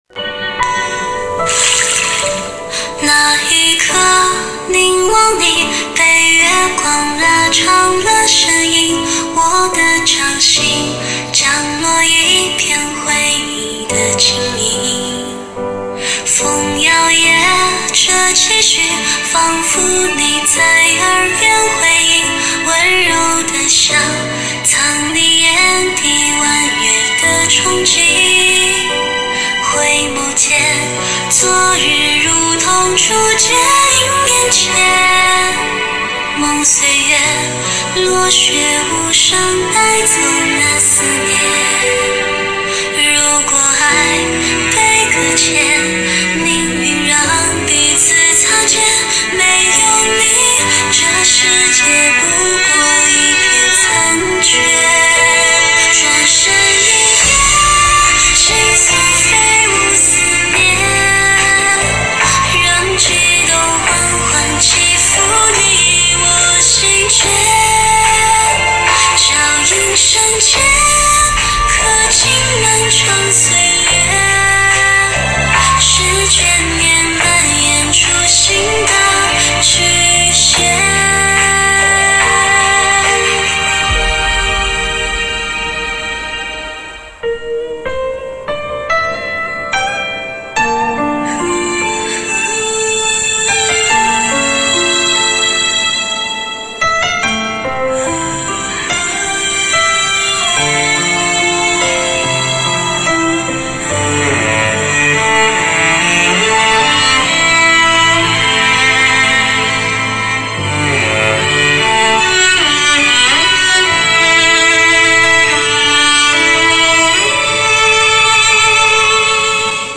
Ps：在线试听为压缩音质节选，体验无损音质请下载完整版 窦公敞华筵墨客尽来臻，燕歌落胡雁郢曲回阳春。